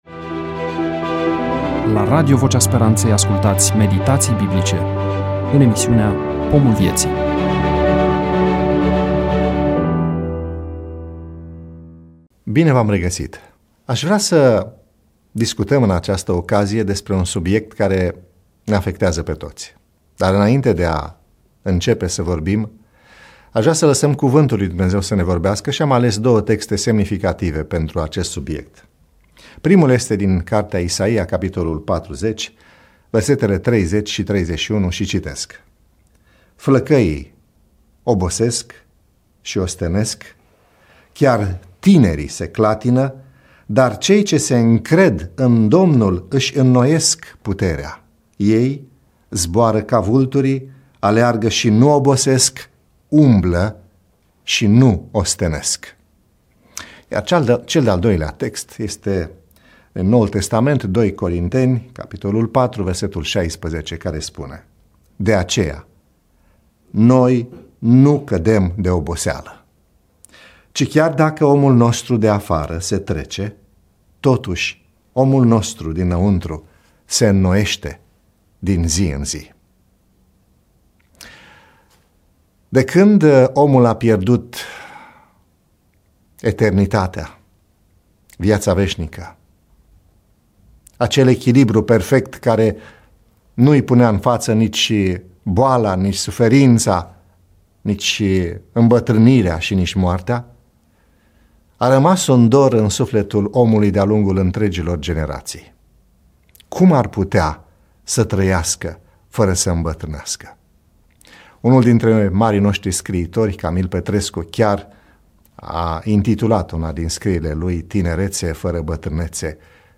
EMISIUNEA: Predică DATA INREGISTRARII: 31.10.2024 VIZUALIZARI: 55